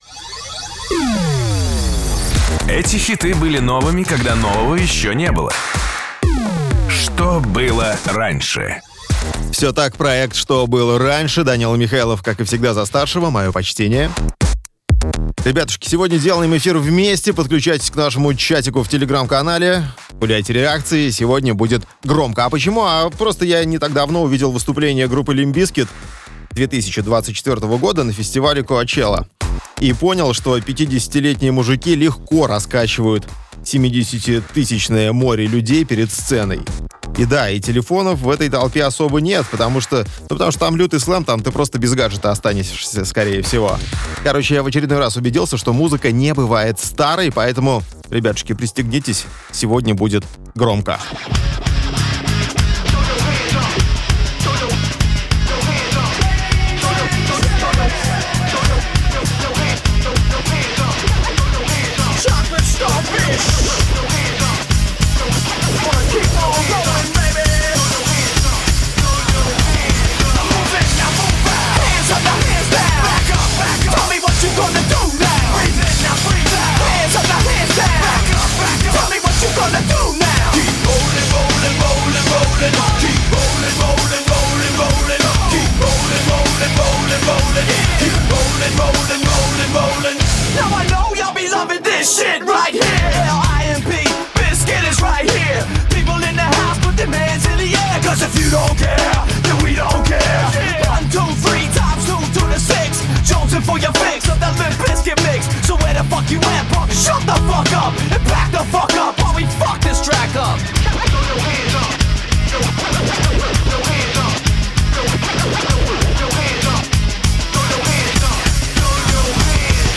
Сегодня будет громко.
Громкий рок.